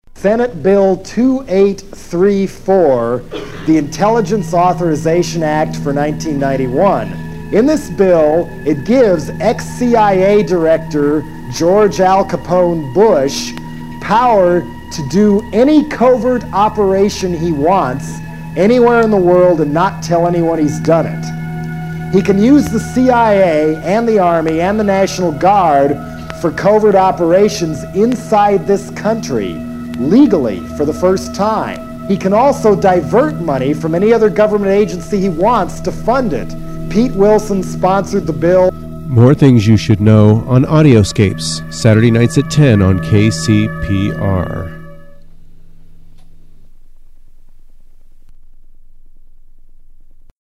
The sample is from a Jello Biafra spoken word album.
Form of original Audiocassette